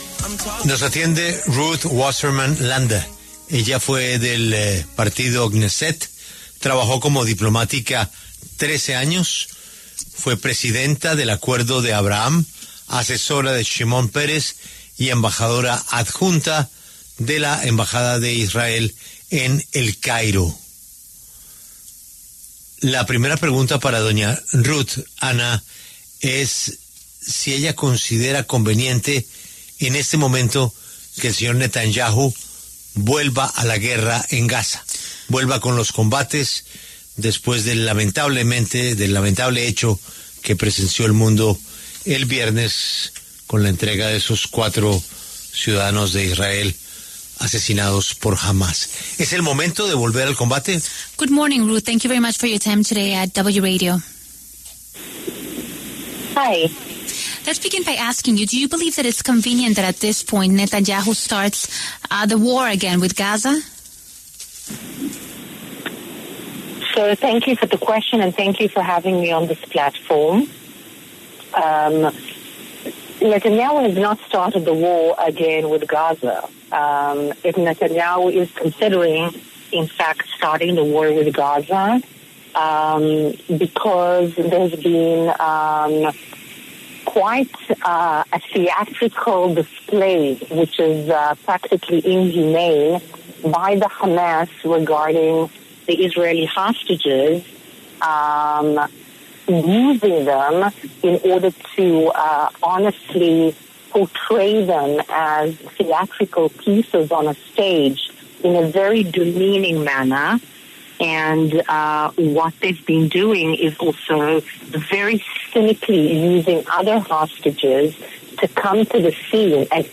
Ruth Wasserman, exmiembro del Knéset y exdiplomática israelí, conversó con La W sobre el panorama de la guerra en Medio Oriente y la posibilidad de acabar con el alto al fuego.